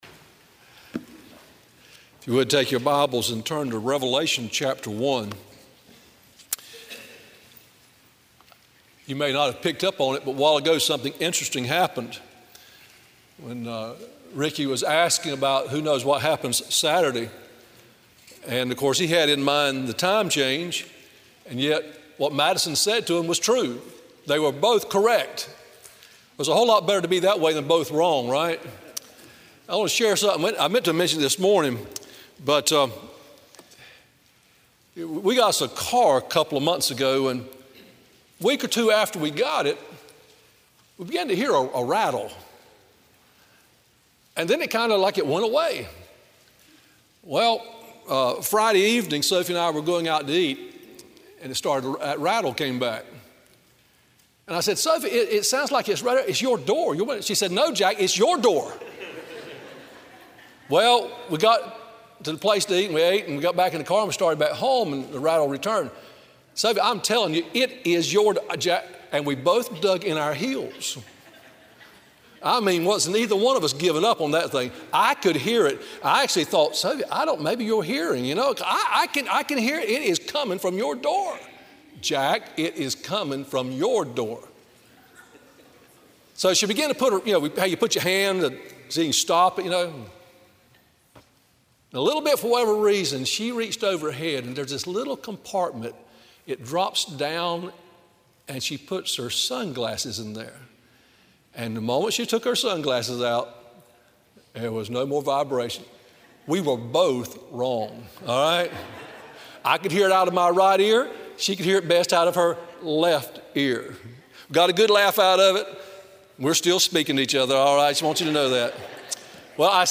Sermon Audios/Videos - Tar Landing Baptist Church
Evening WorshipRevelation 1:1-6